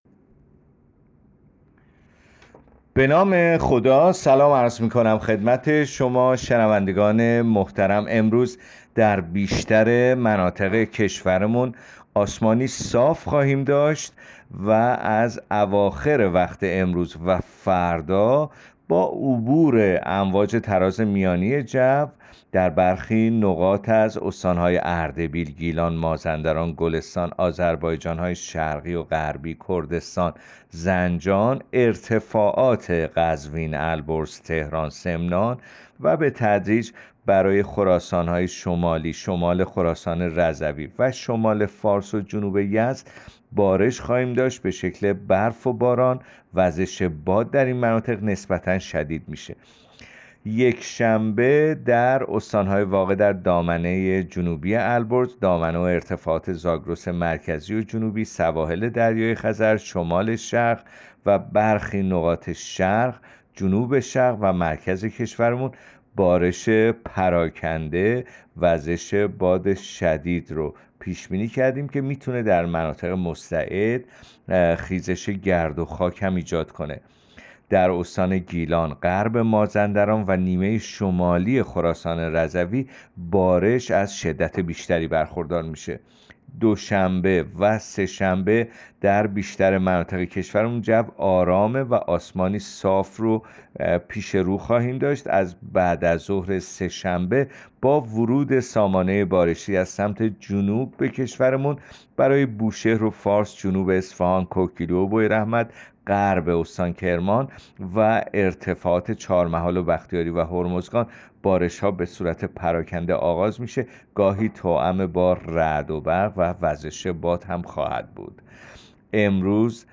گزارش آخرین وضعیت جوی کشور را از رادیو اینترنتی پایگاه خبری وزارت راه و شهرسازی بشنوید.
گزارش رادیو اینترنتی پایگاه‌ خبری از آخرین وضعیت آب‌وهوای ۱۰ اسفند؛